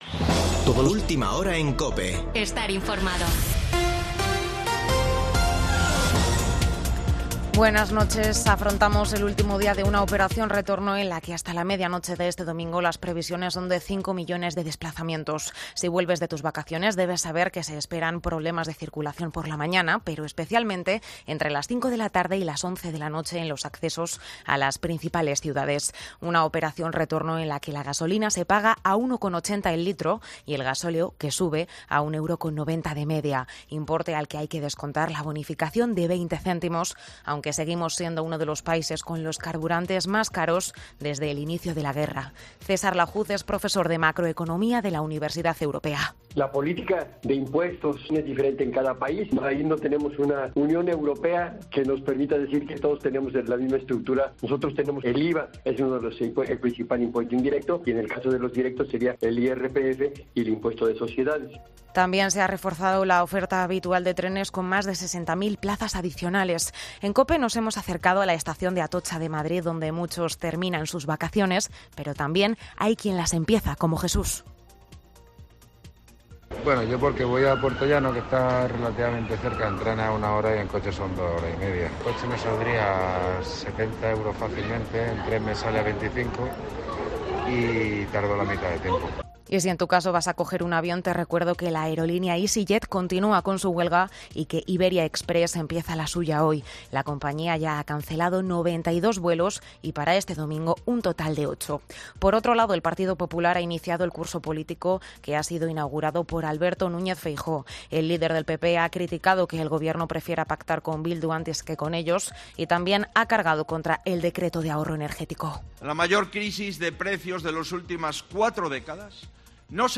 Boletín de noticias de COPE del 28 de agosto de 2022 a la 01.00 horas